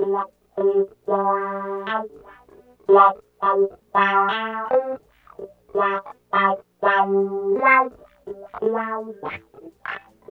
44 GUITAR -R.wav